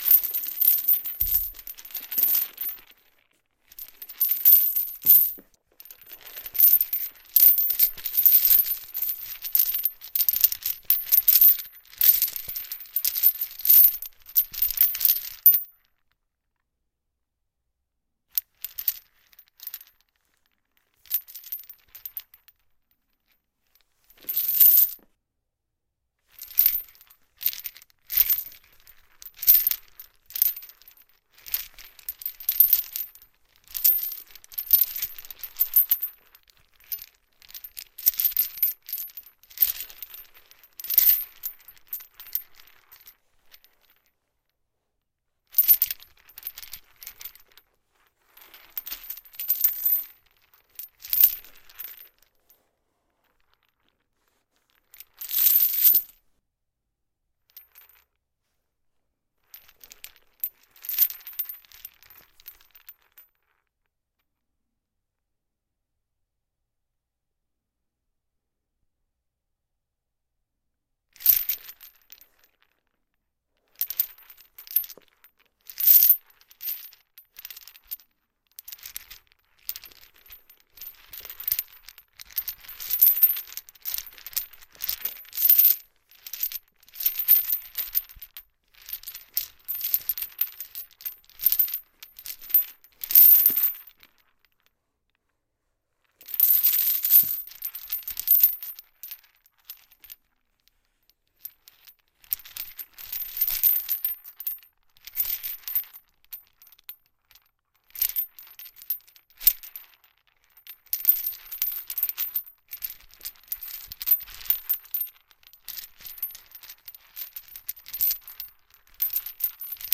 随机" 链条粗的处理异响
描述：链厚处理rattle.wav
Tag: 处理 拨浪鼓